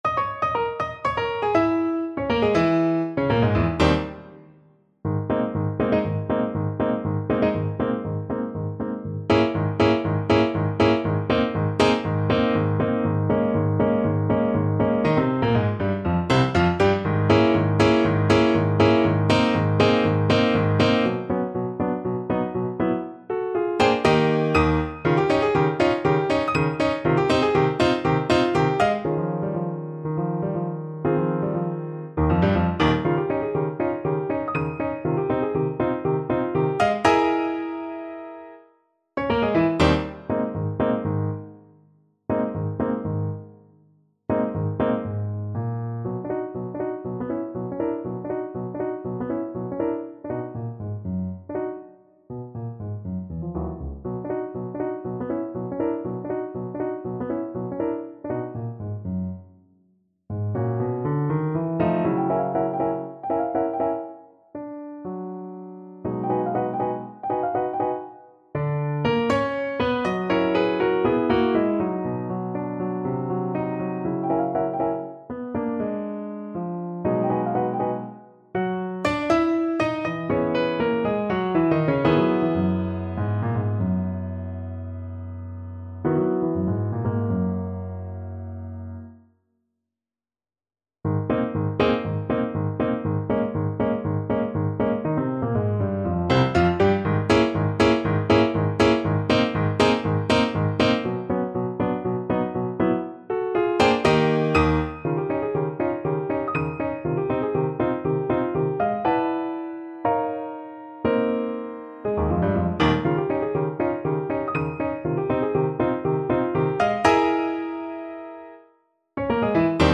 • Unlimited playalong tracks
Allegro giusto (View more music marked Allegro)
2/4 (View more 2/4 Music)